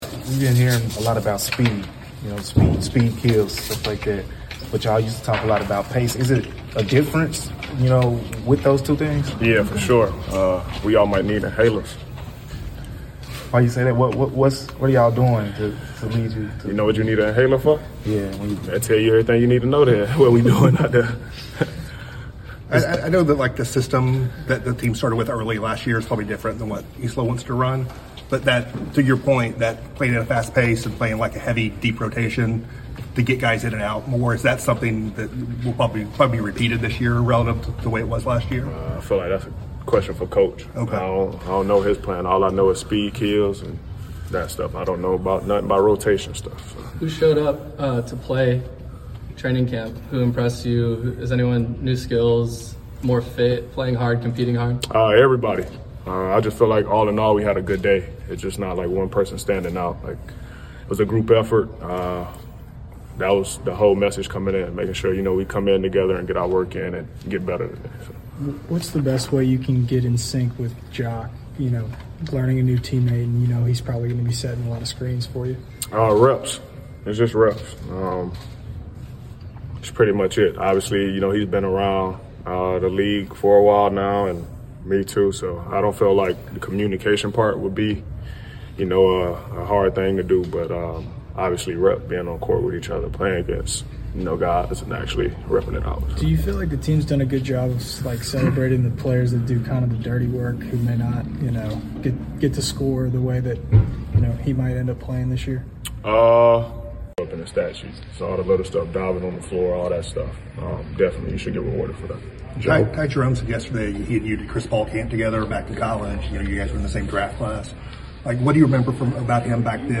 Memphis Grizzlies Guard Ja Morant Press Conference after the first day of Training Camp.